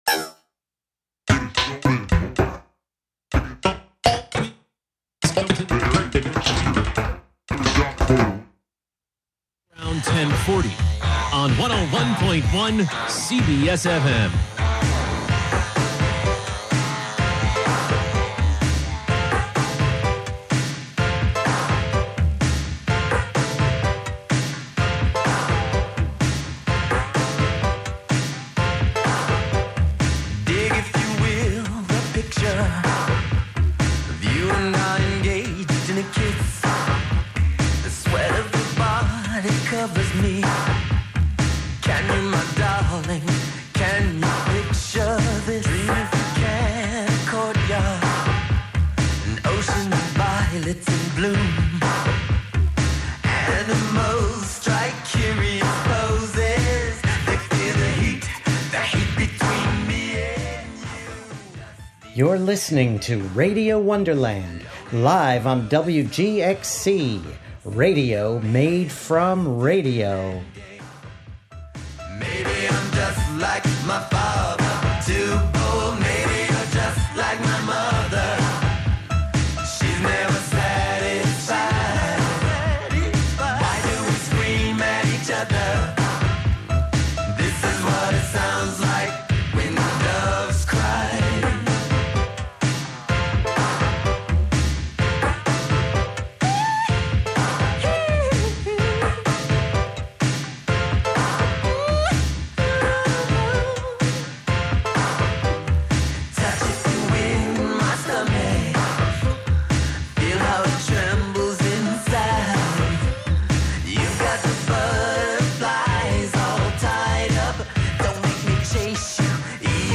11am Live from Brooklyn, New York
making instant techno 90 percent of the time
play those S's, T's and K's like a drum machine